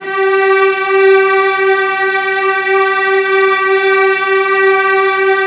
CELLOS AN4-L.wav